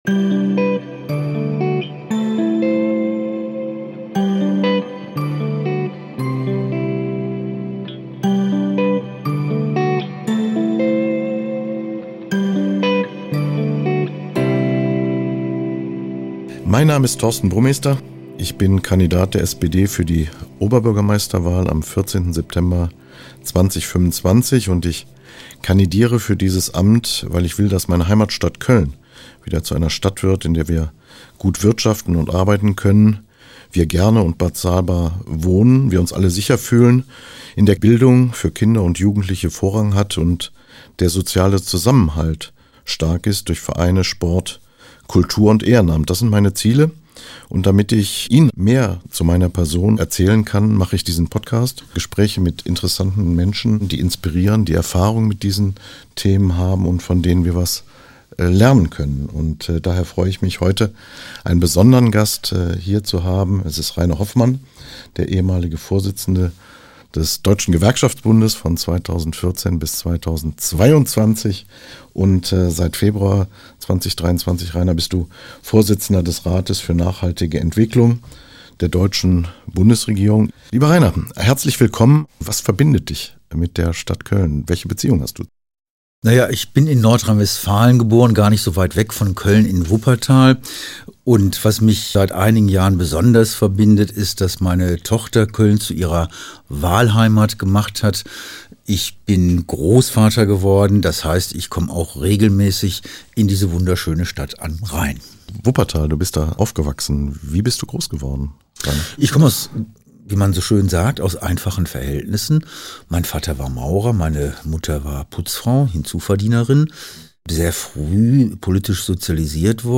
Im Gespräch mit Köln.